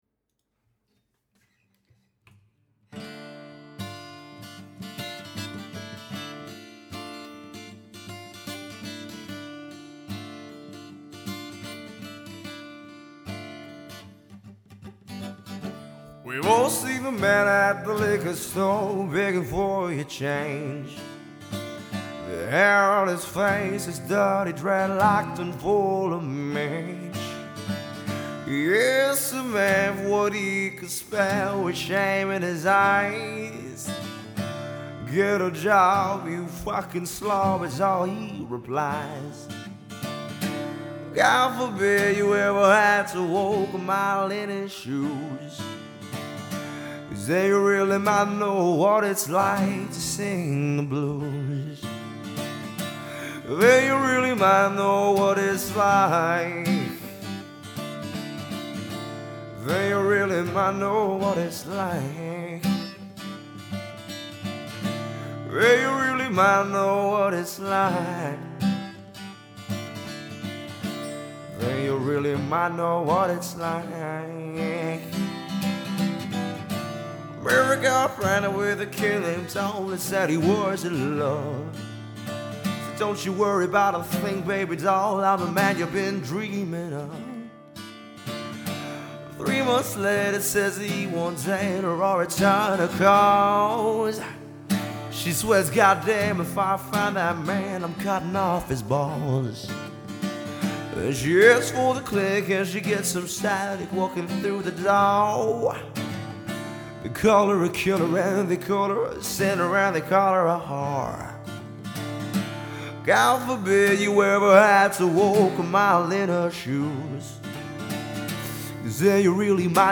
Mångsidig Trubadur med erfarenhet och bred repertoar
• Trubadur